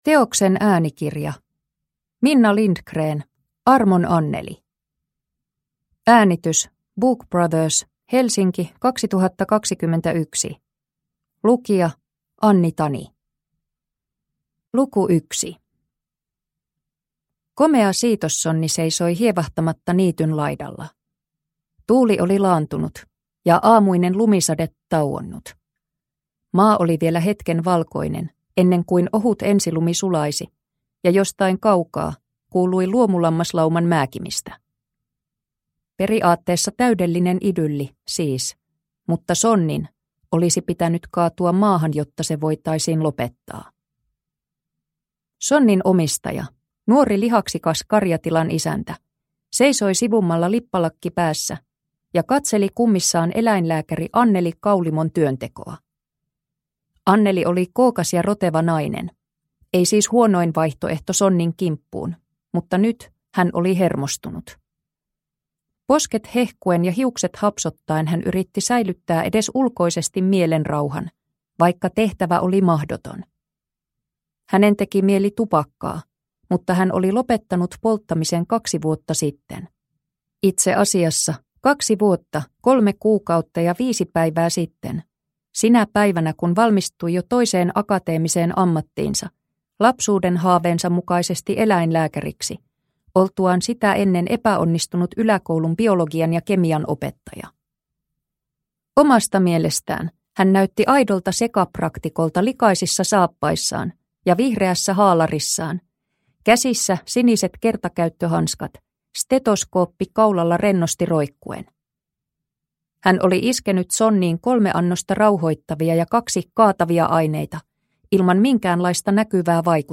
Armon Anneli – Ljudbok – Laddas ner